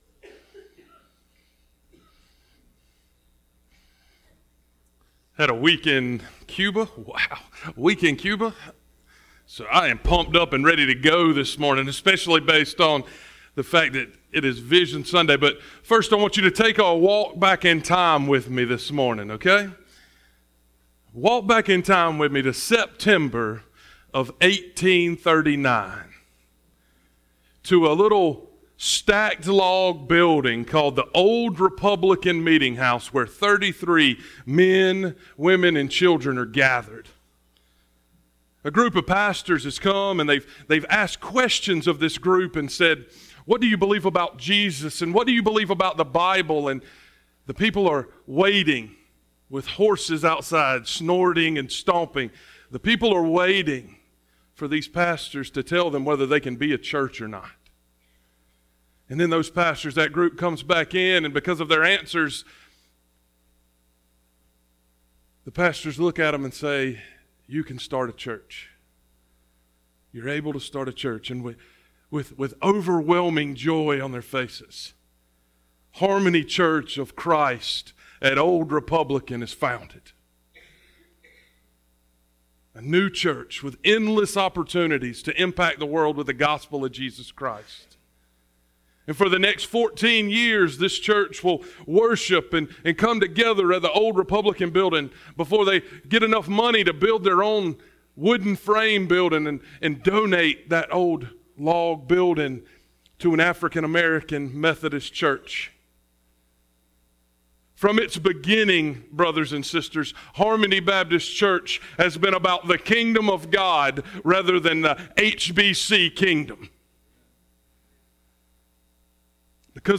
Vision Sunday Sermon